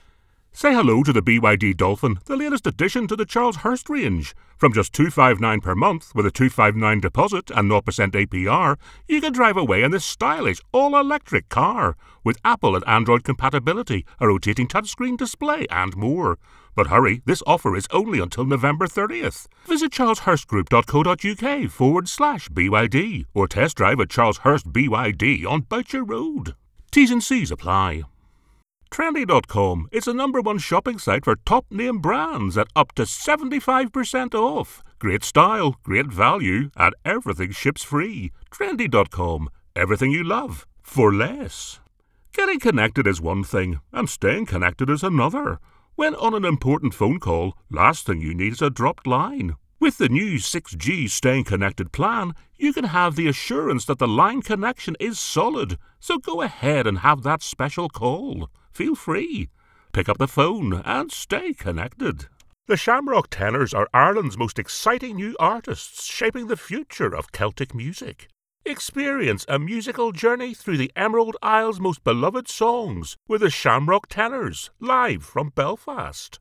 A Northern Irish Voice
Radio Commercials
I have a Northern Irish Accent which can be street or upperclass.
I have a deep, versatile, powerful voice, thoughtful , authoritative , storytelling and funny .